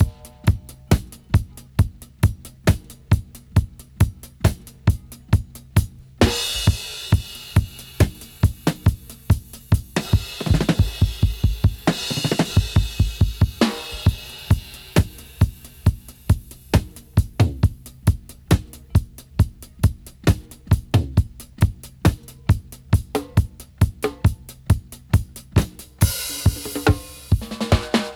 136-DRY-01.wav